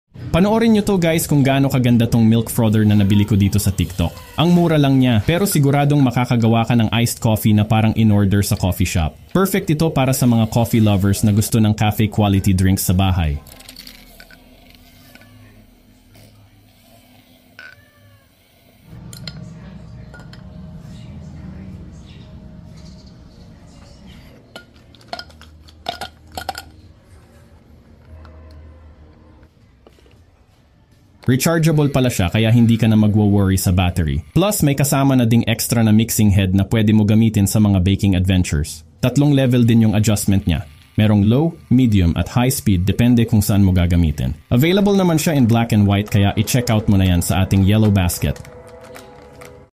Trending Coffee and Milk Frother sound effects free download